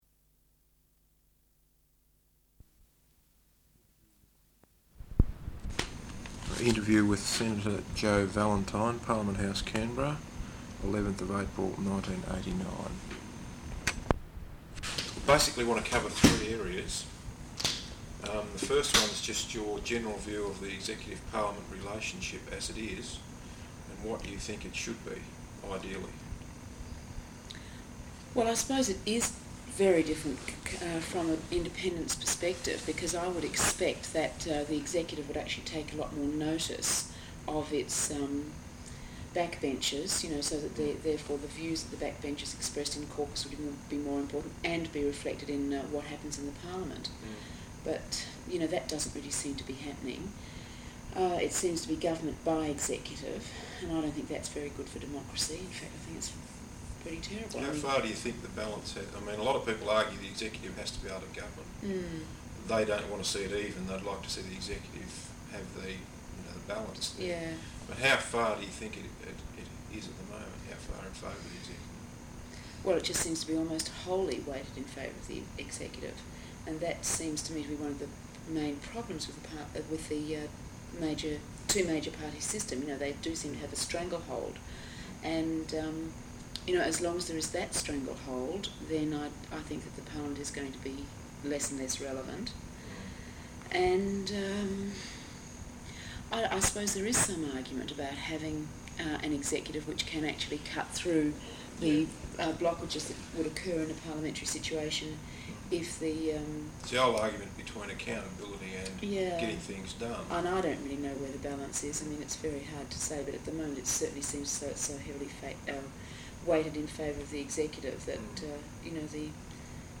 Interview with Senator Jo Vallentine, Parliament House, Canberra. 11th of April, 1989.